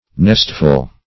nestful - definition of nestful - synonyms, pronunciation, spelling from Free Dictionary Search Result for " nestful" : The Collaborative International Dictionary of English v.0.48: Nestful \Nest"ful\, n.; pl. Nestfuls .